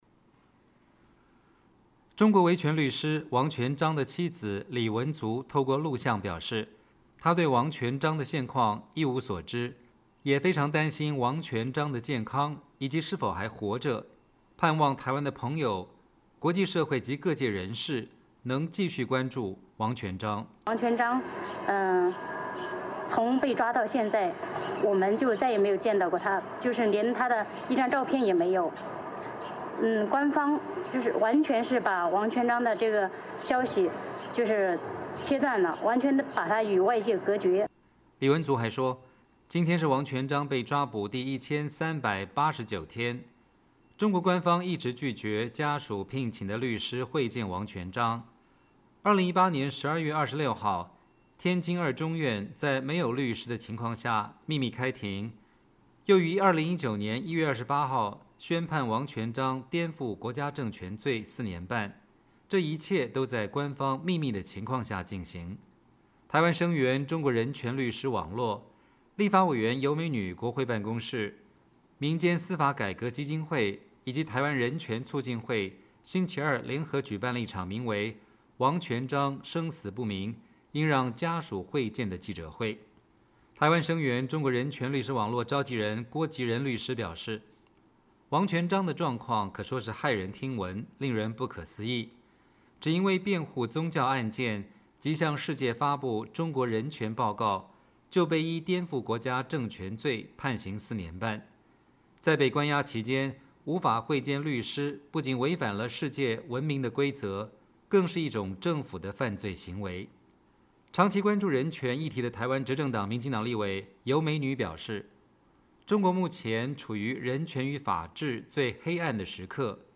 2019年4月30日，台湾人权团体及立法委员召开“王全璋生死不明 应让家属会见”记者会声援中国维权律师王全璋